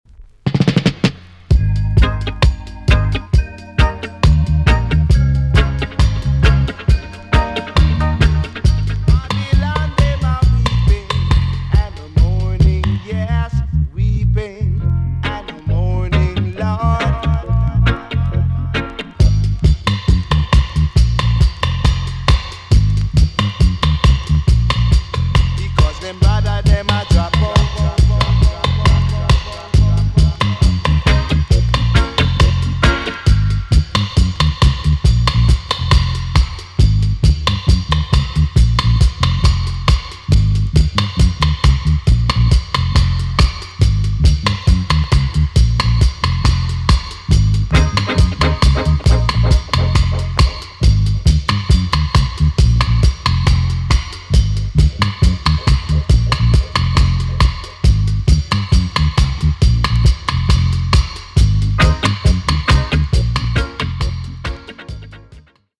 TOP >REGGAE & ROOTS
B.SIDE Version
EX- 音はキレイです。